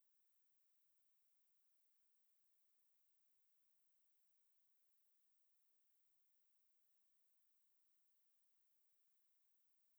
Converted sound file to a 10 second mono noise with lower volume. Tested with max possible sound volume and it was hardly audible.
prevent-app-nap-silent-sound.aiff